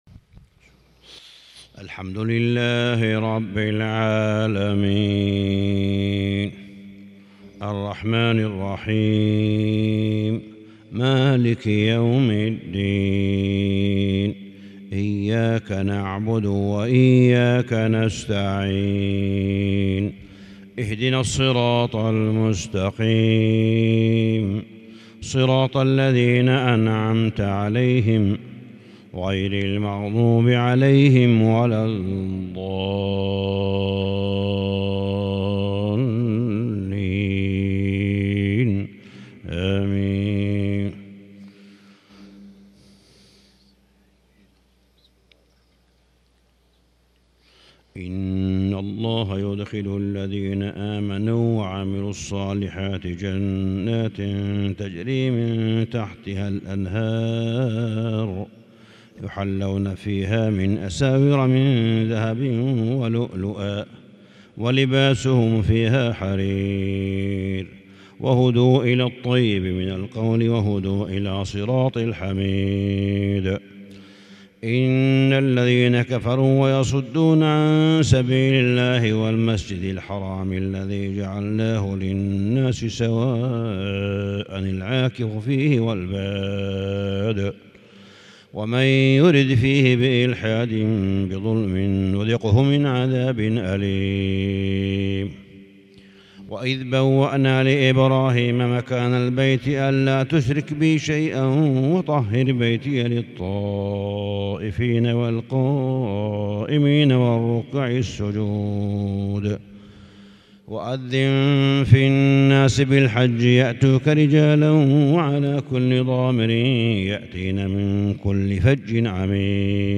صلاة الفجر للشيخ صالح بن حميد 6 ذو الحجة 1442 هـ
تِلَاوَات الْحَرَمَيْن .